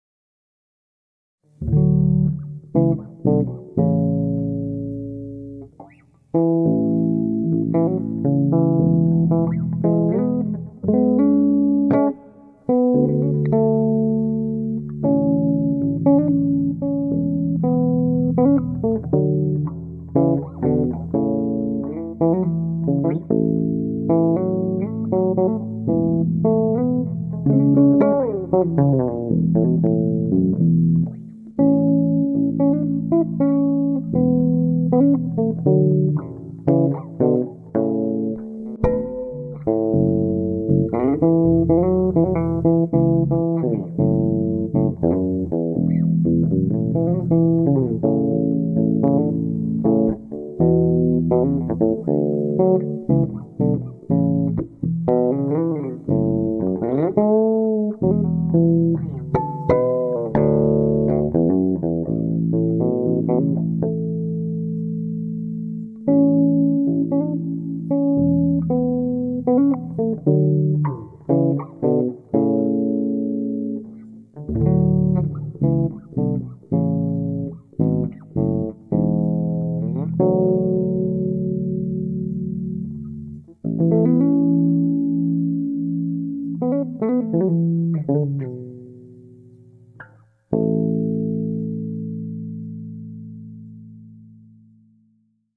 (arrangement bass cover)